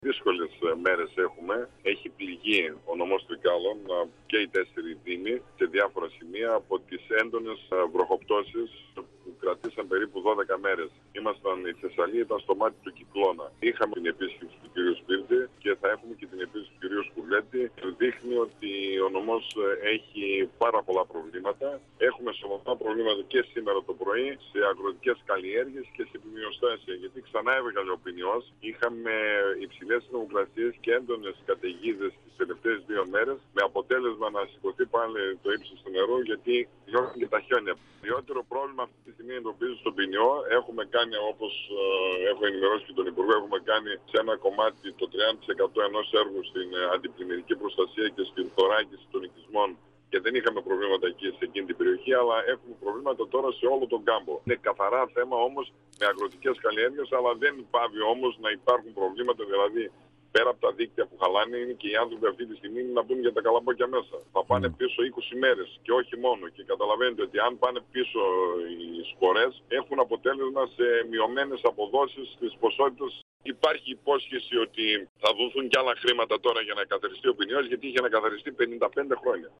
Ο αντιπεριφερειάρχης Τρικάλων, Χρήστος Μιχαλάκης, στον 102FM του Ρ.Σ.Μ. της ΕΡΤ3
Συνέντευξη